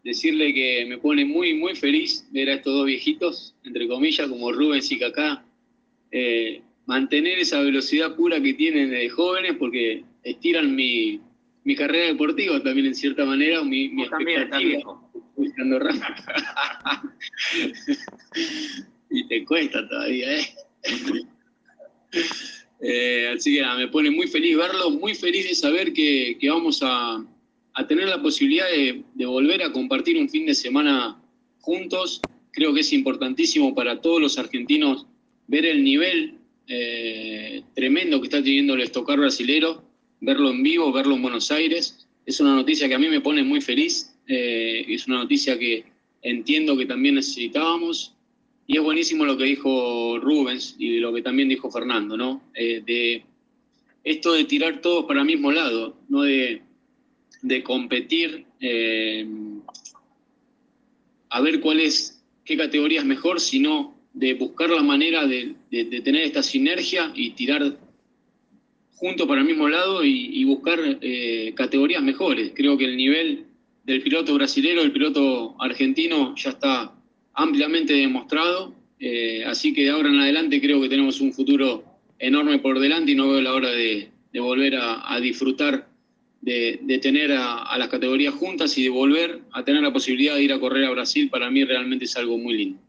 El campeón de la categoría estuvo presente en la conferencia de prensa que se desarrolló en el ACA y manifestó su alegría por volver a competir junto al Stock Car y volver a competir en el exterior, con la visita de la categoría en 2024 a Interlagos.